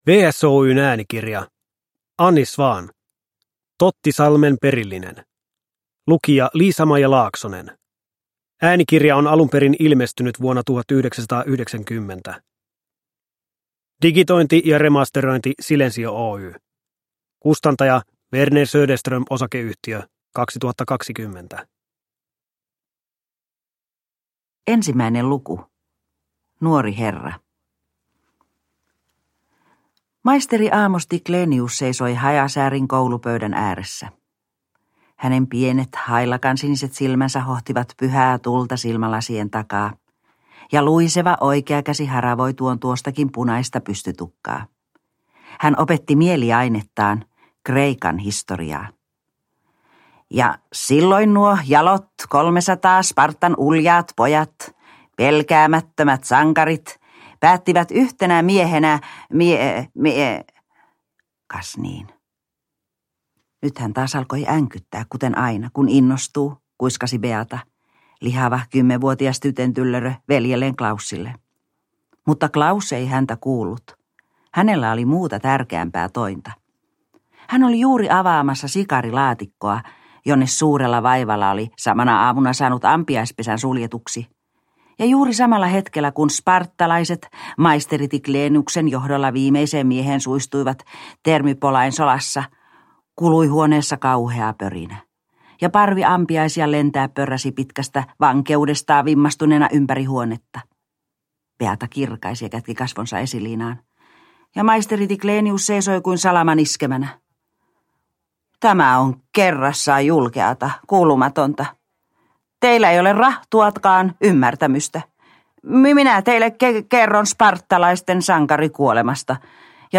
Tottisalmen perillinen – Ljudbok – Laddas ner
Äänikirja on äänitetty alun perin 1990, digitoitu 2020.